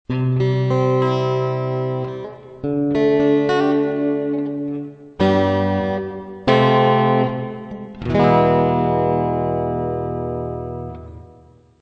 ♪この素材、リバーブ掛かってるにも関わらず♪このように短調へ変換できた（リバーブもちゃんと追従）。大きくピッチを移動させて音色が不自然になってもフォルマント調整で馴染ませることができる。